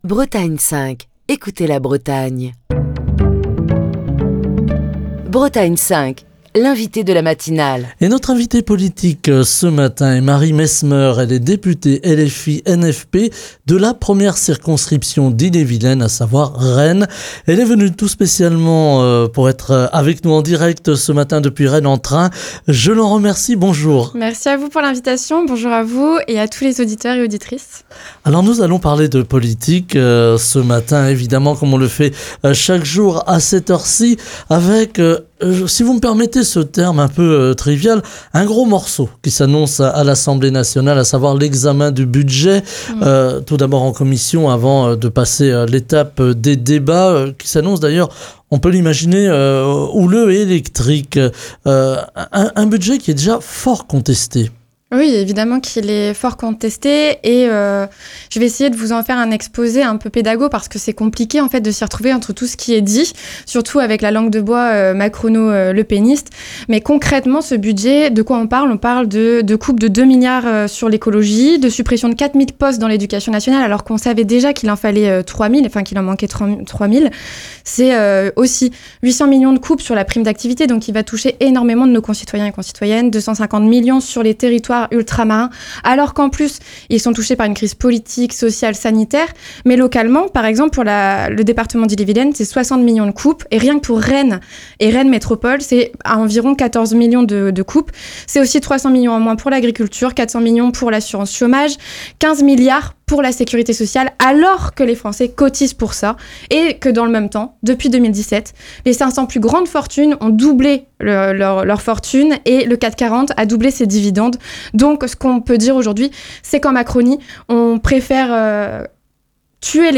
Ce lundi Marie Mesmeur, députée LFI-NFP de la 1ère circonscription d’Ille-et-Vilaine (Rennes Sud), est l'invitée de la matinale de Bretagne 5 pour évoquer les conséquences sociales, mais aussi au niveau des collectivités, de ce budget sur fond d'austérité.